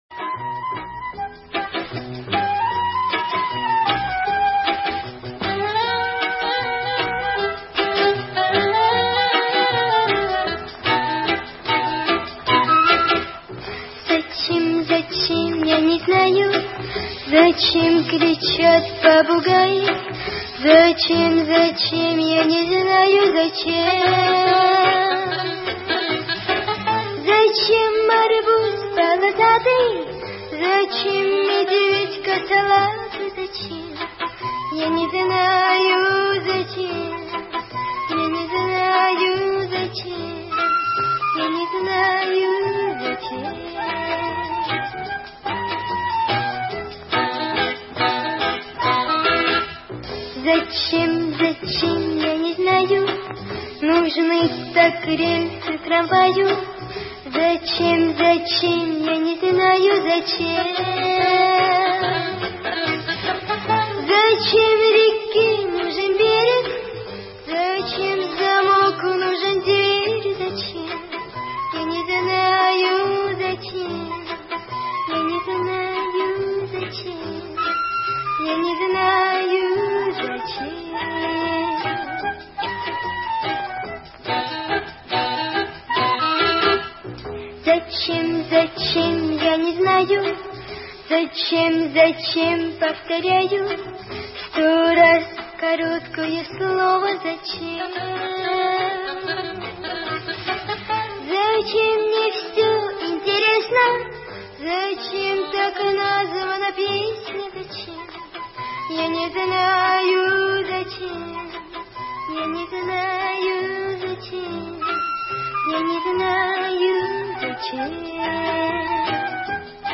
Поёт та самая маленькая девочка, давно уже ставшая взрослой: